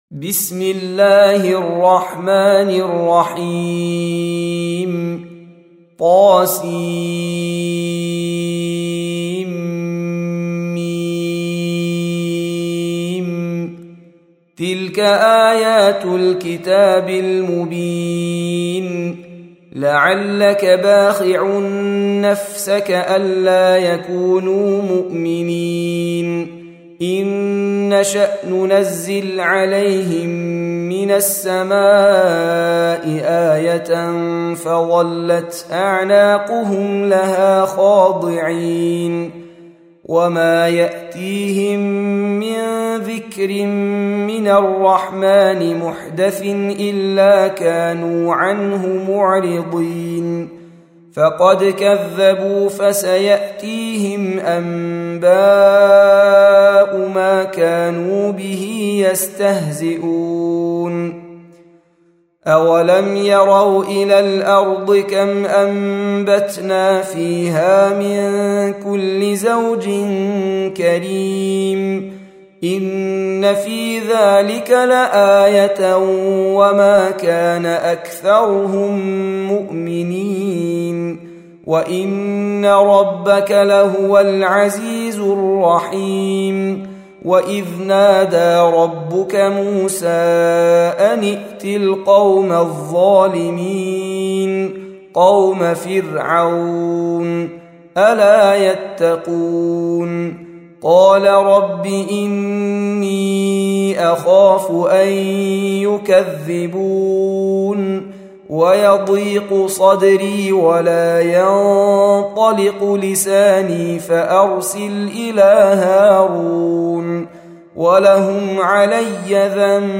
Audio Quran Tarteel Recitation
Surah Sequence تتابع السورة Download Surah حمّل السورة Reciting Murattalah Audio for 26. Surah Ash-Shu'ar�' سورة الشعراء N.B *Surah Includes Al-Basmalah Reciters Sequents تتابع التلاوات Reciters Repeats تكرار التلاوات